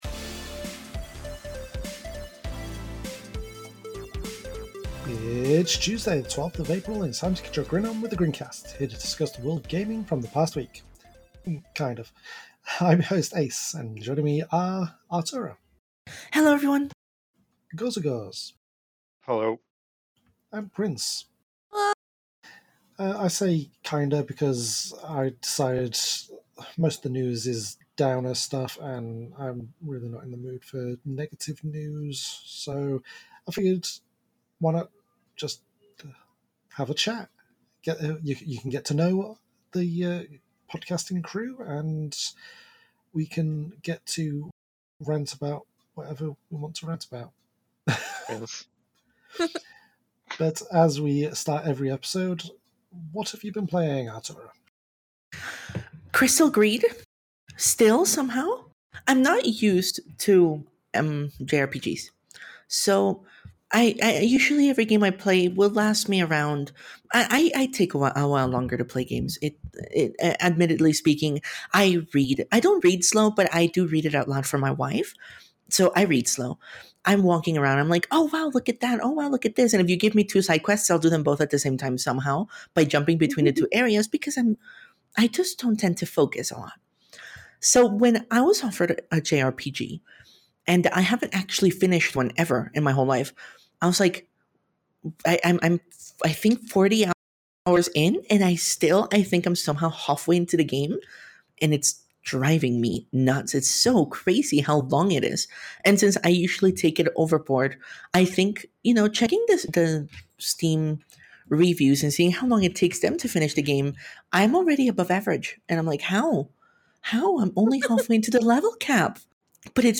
Our main topic this week: A chill chat.